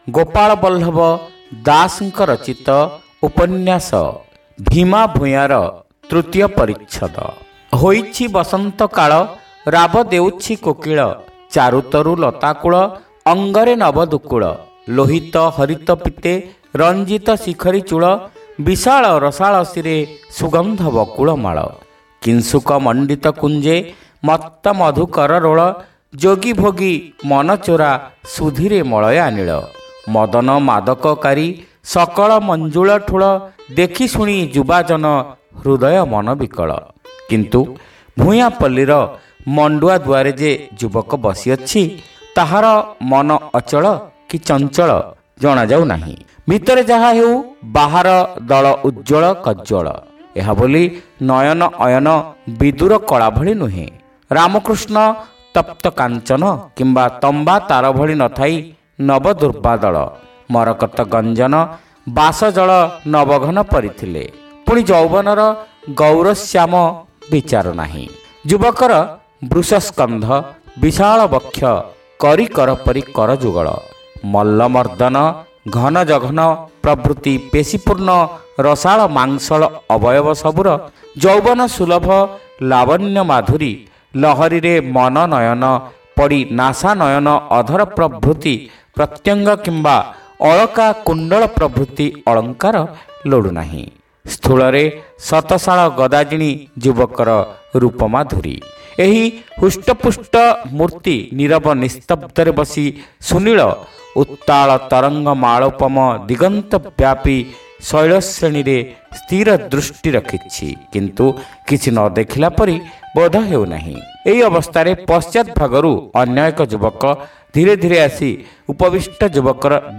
ଶ୍ରାବ୍ୟ ଉପନ୍ୟାସ : ଭୀମା ଭୂୟାଁ (ତୃତୀୟ ଭାଗ)